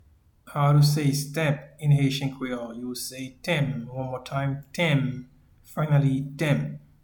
Pronunciation and Transcript:
Stamp-in-Haitian-Creole-Tenm.mp3